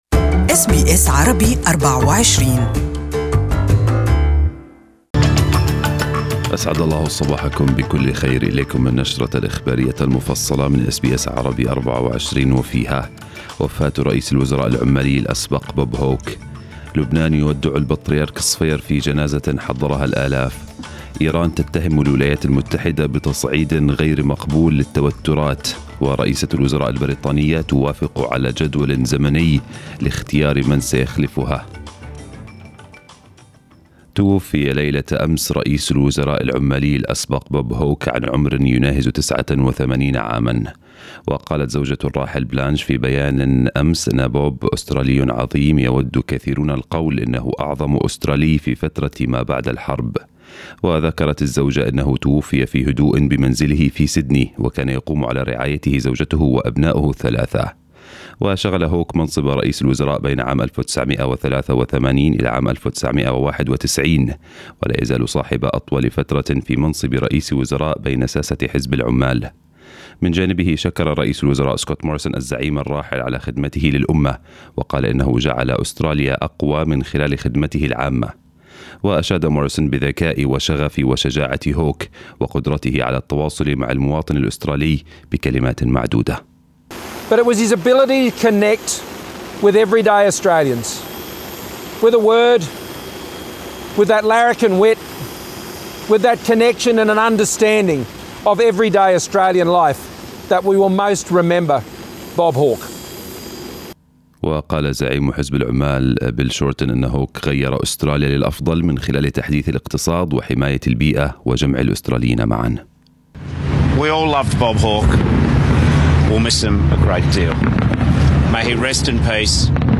News Bulletin in Arabic this morning 17/5/2019